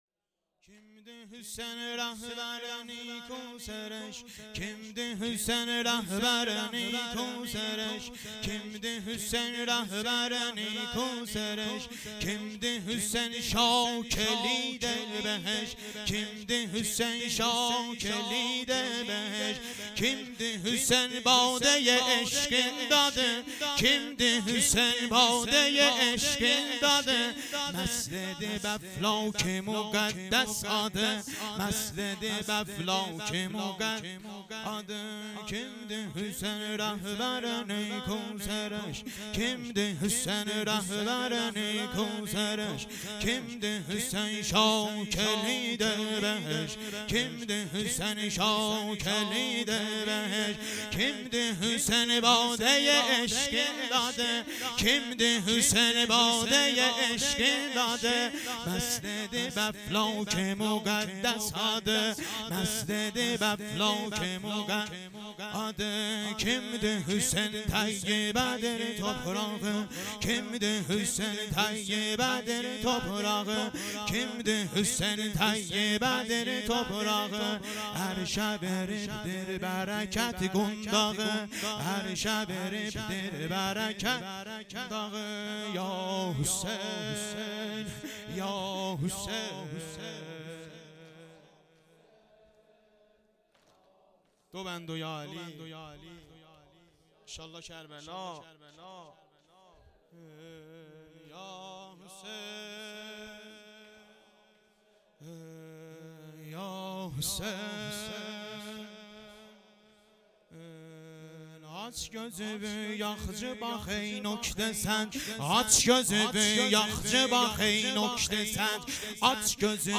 واحد / هیئت انصار الحیدر زنجان